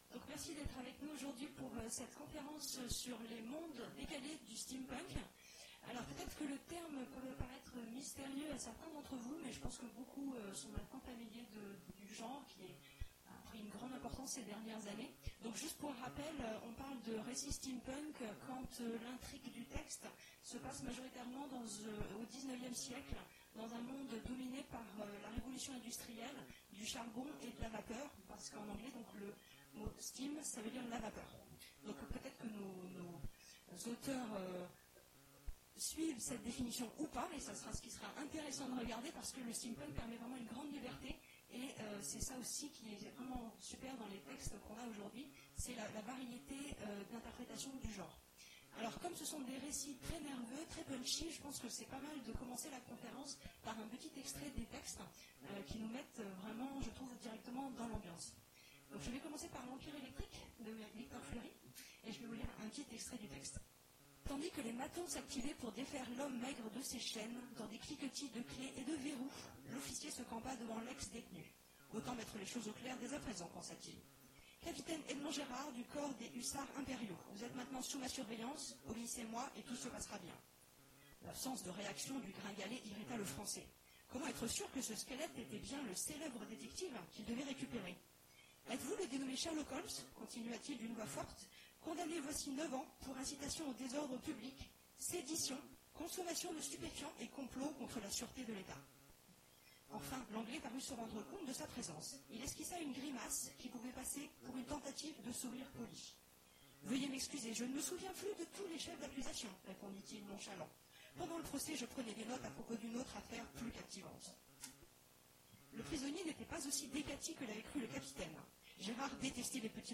Mots-clés Steampunk Conférence Partager cet article